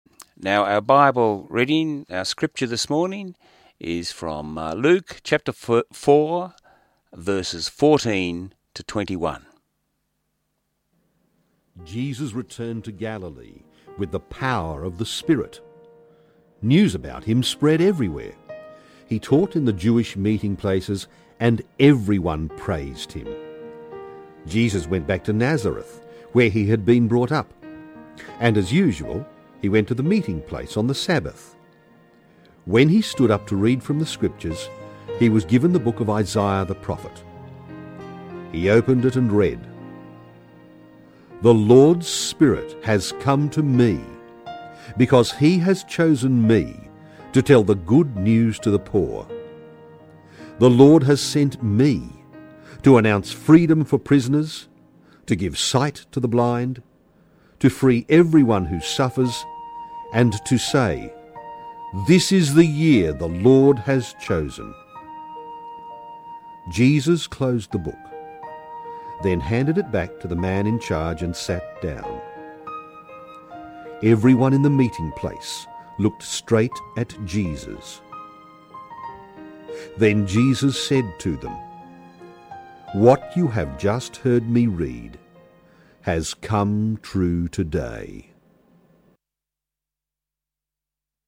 Our scripture reading on 27Jan19 was Luke 4 verses 14 to 21, Jesus speaks in the synagogue.